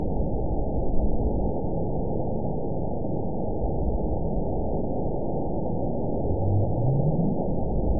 event 920485 date 03/27/24 time 23:08:41 GMT (1 month ago) score 9.64 location TSS-AB02 detected by nrw target species NRW annotations +NRW Spectrogram: Frequency (kHz) vs. Time (s) audio not available .wav